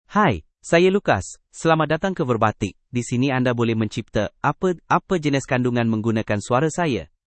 LucasMale Malayalam AI voice
Lucas is a male AI voice for Malayalam (India).
Voice sample
Listen to Lucas's male Malayalam voice.
Male
Lucas delivers clear pronunciation with authentic India Malayalam intonation, making your content sound professionally produced.